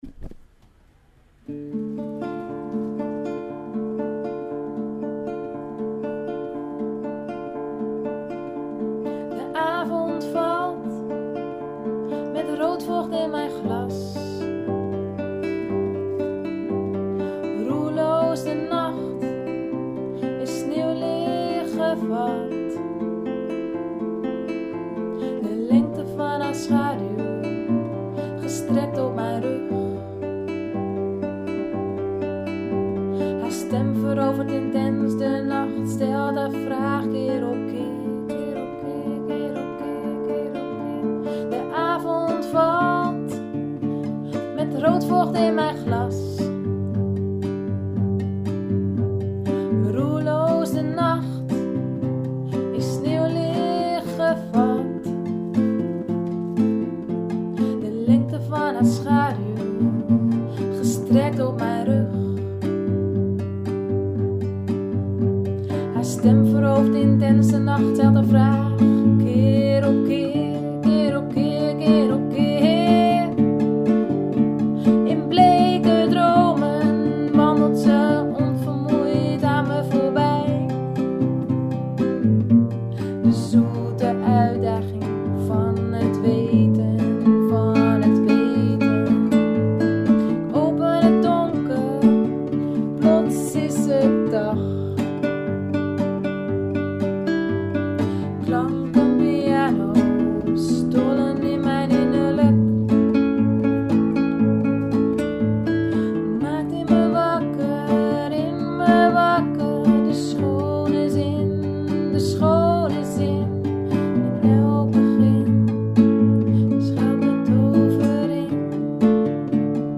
Twee versies in de studio opgenomen
gitaar
zang